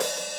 AS-OHH.wav